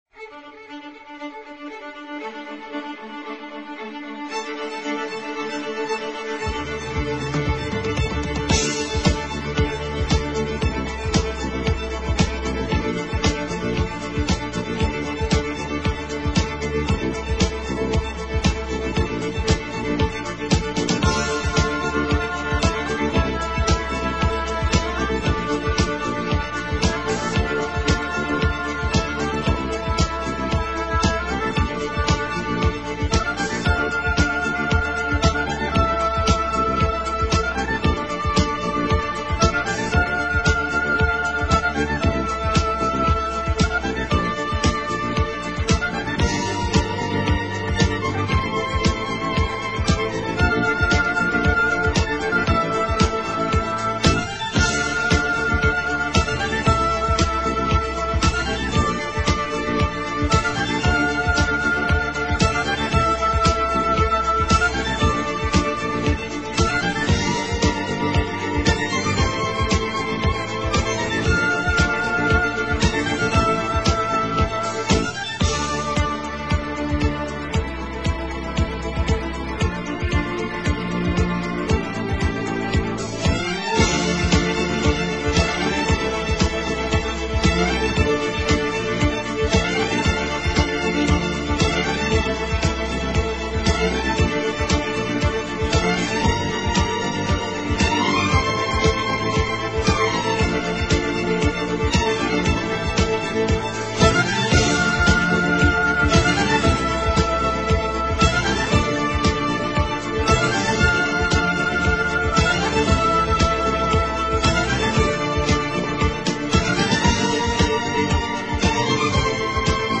【轻音乐专辑】
音乐风格： 其他|古典|(Class)